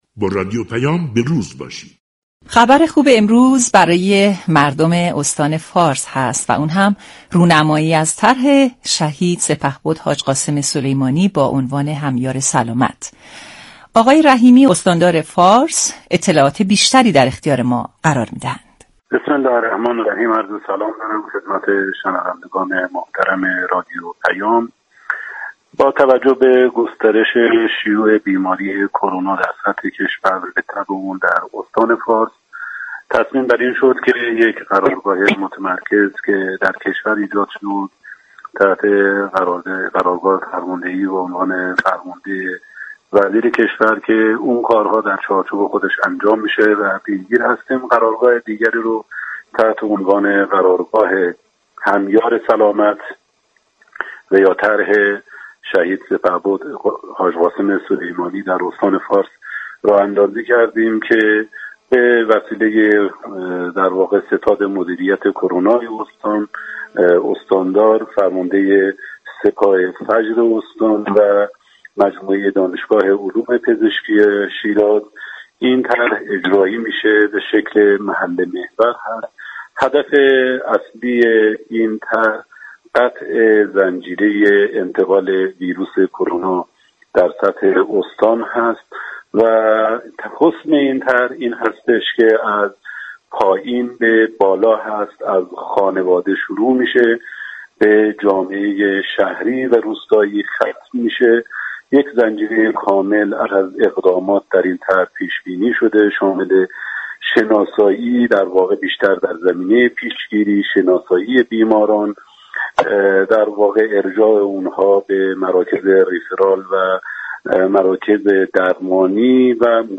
رحیمی ، استاندار فارس در گفتگو با رادیو پیام ، از آغاز طرح همیار سلامت شهید سپهبد حاج قاسم سلیمانی با هدف پیشگیری از گسترش بیماری و قطع كردن زنجیره انتقال ویروس كرونا ، بیماریابی و كمك به كادر بهداشت و درمان در استان فارس خبر داد .